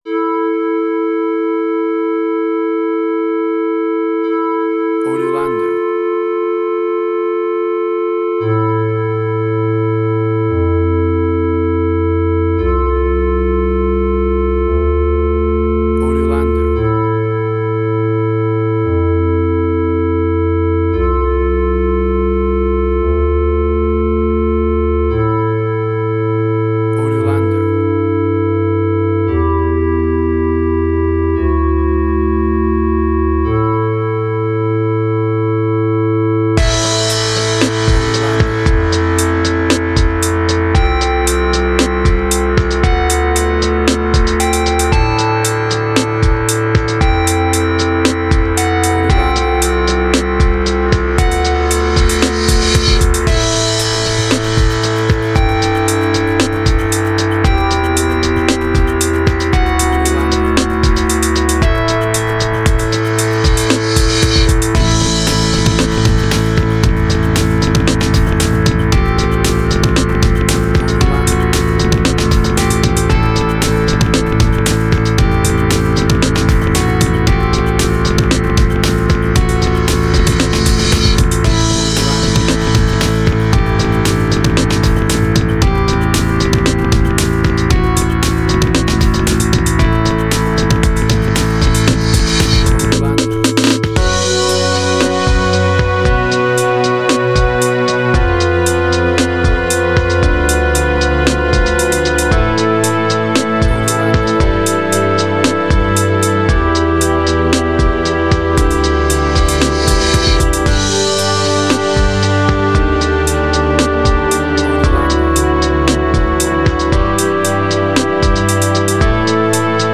IDM, Glitch.
Tempo (BPM): 58